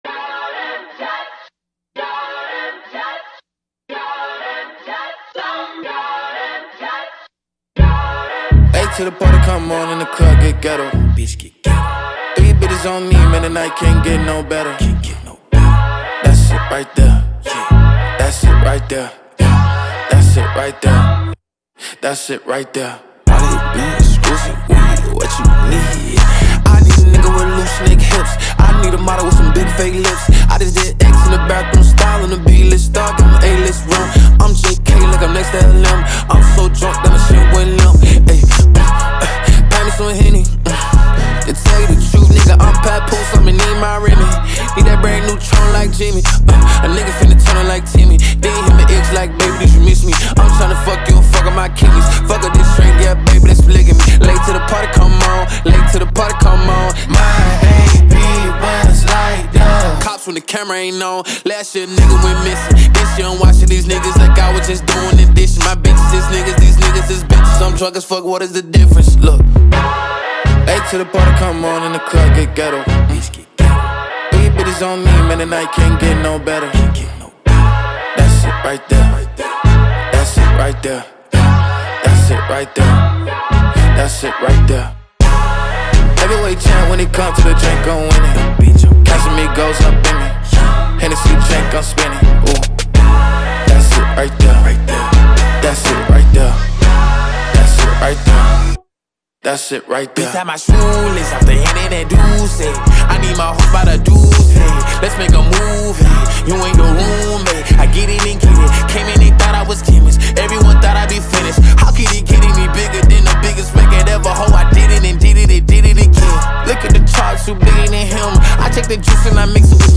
• Жанр: Rap